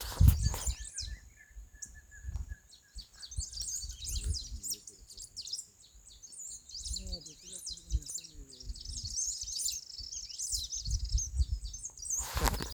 Pampa Finch (Embernagra platensis)
Province / Department: Entre Ríos
Detailed location: Ruta 5 y Arroyo Feliciano
Condition: Wild
Certainty: Observed, Recorded vocal